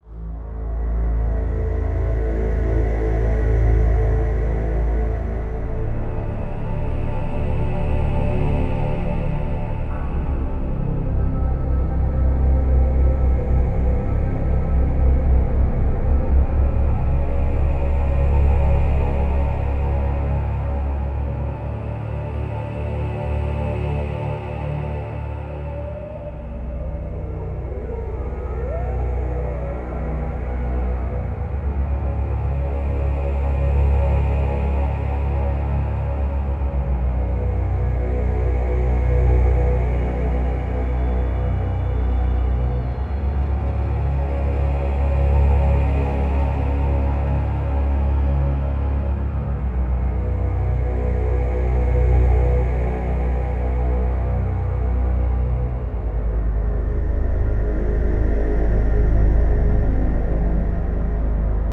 Perfect for ambient, atmosphere, building.